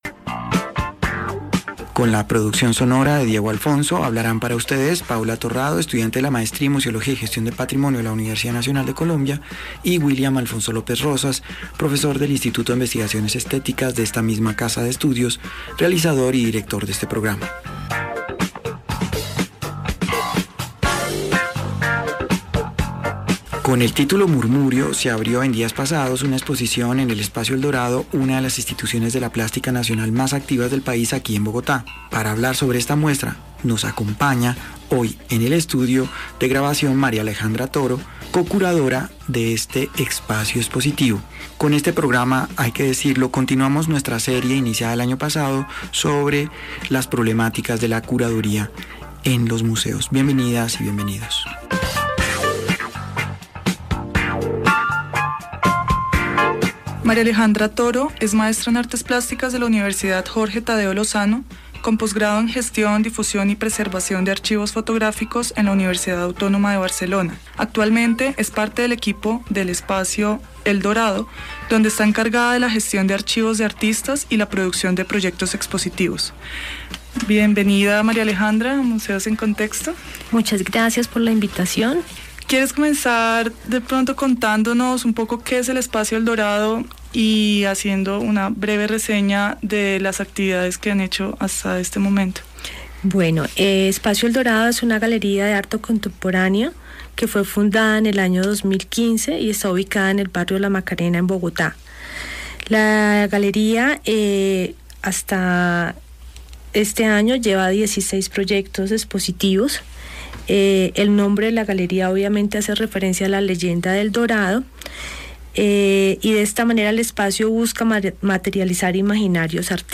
Escucha la entrevista completa en UN Radio